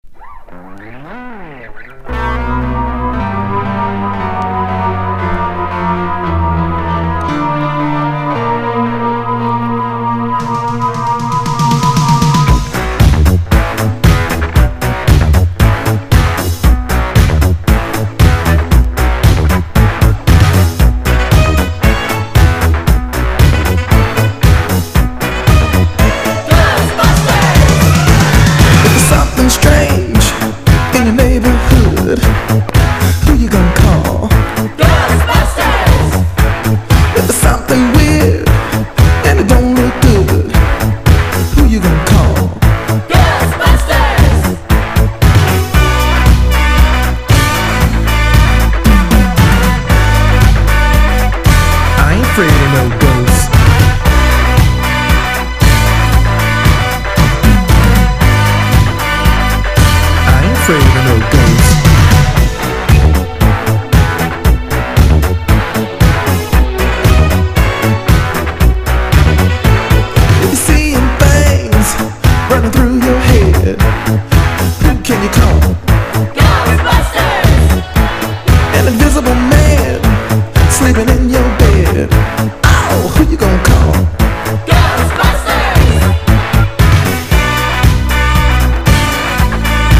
# 80’s ROCK / POPS